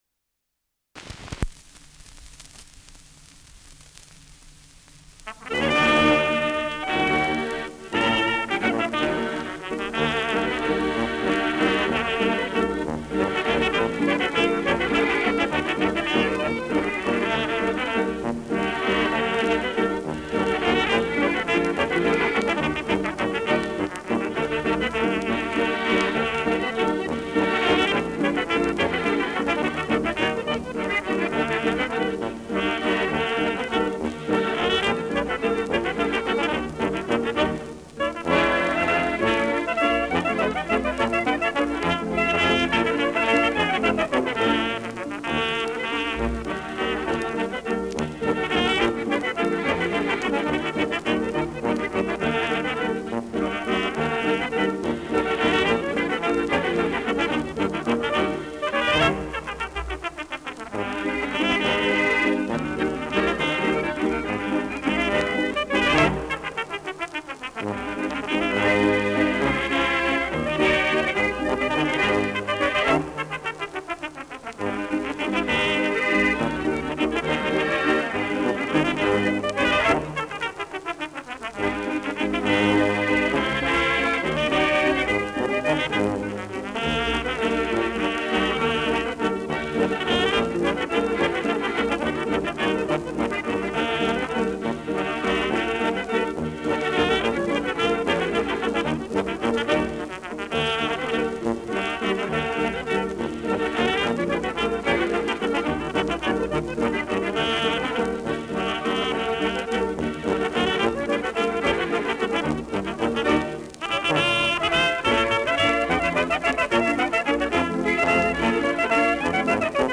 Schellackplatte
Tonrille: Kratzer 9 Uhr Leicht